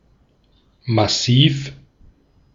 Ääntäminen
IPA : /ˈhɛv.i/